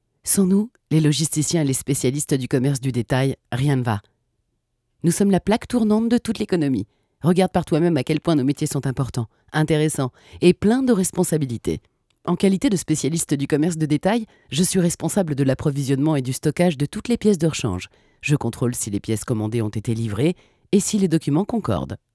Une voix profonde, chaude, suave, élégante et chaleureuse, au service de votre communication.
Une voix grave, chaleureuse et sexy si besoin, bien d’autres variations sont disponibles.
Souriante…
so_com_souriant.wav